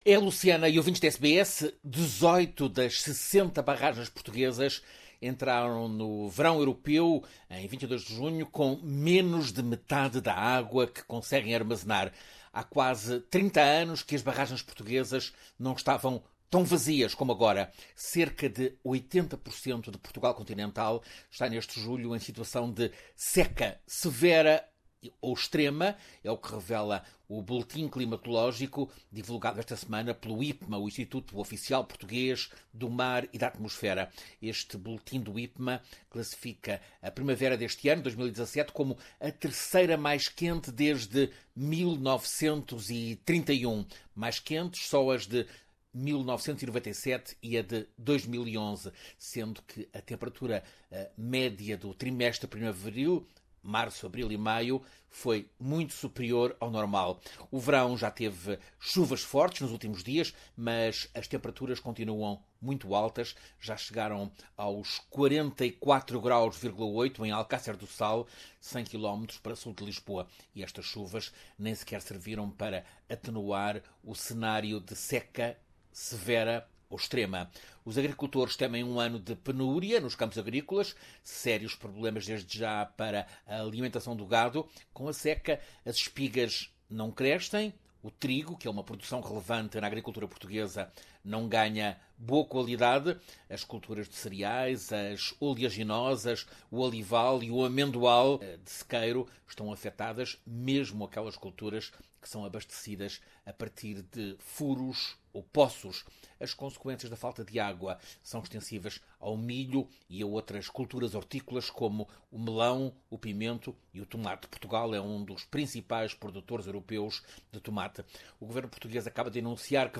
Alerta em Portugal para situação grave de seca com as represas de água praticamente vazias. Ouça reportagem